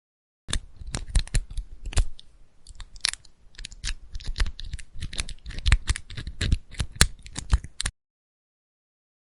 Picking Padlock
SFX
yt_0XbMDiQxgtA_picking_padlock.mp3